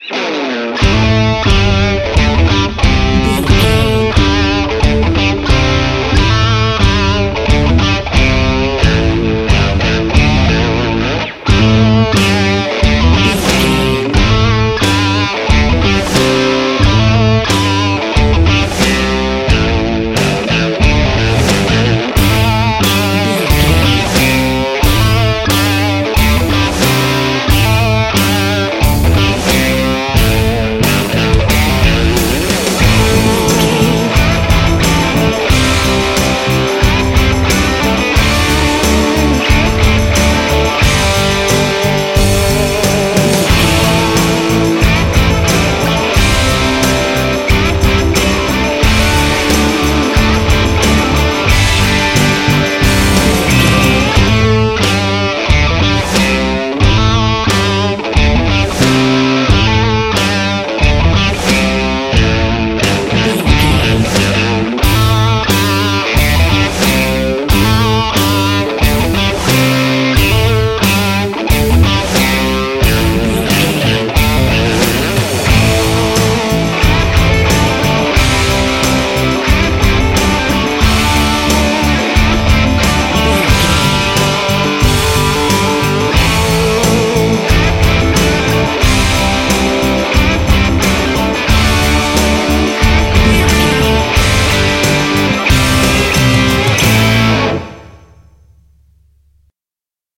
Epic / Action
Ionian/Major
bass guitar
drum machine
electric guitar
percussion
aggressive
intense
energetic
heavy